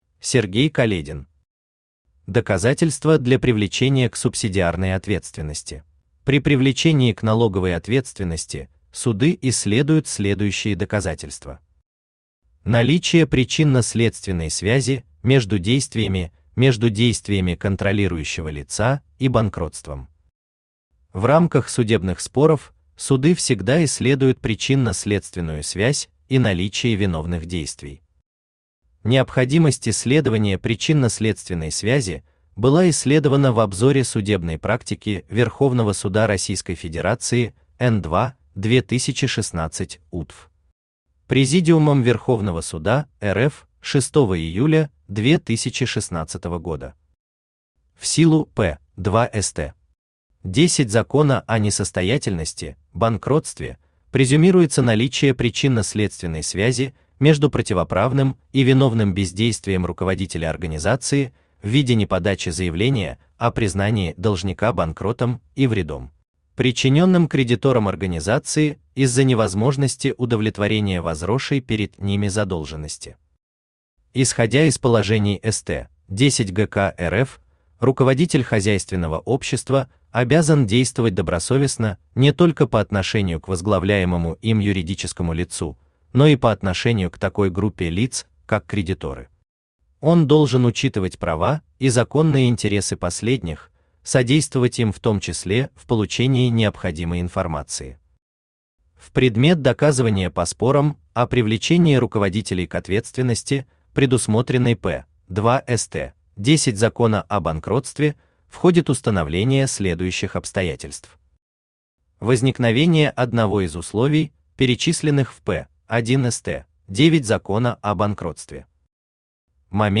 Aудиокнига Доказательства для привлечения к субсидиарной ответственности Автор Сергей Каледин Читает аудиокнигу Авточтец ЛитРес.